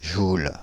Ääntäminen
Ääntäminen Paris: IPA: [ʒul] France (Île-de-France): IPA: /ʒul/ Haettu sana löytyi näillä lähdekielillä: ranska Käännös Konteksti Substantiivit 1. joule fysiikka, metrologia Suku: m .